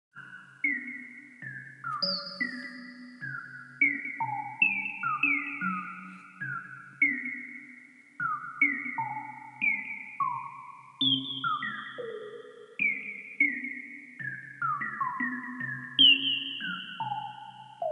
Synth